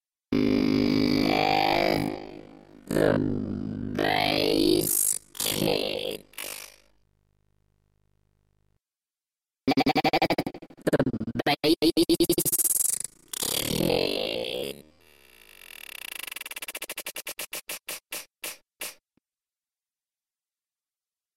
You will hear the sample retriggering from different starting points, giving a stuttered, ‘time stretch’ effect.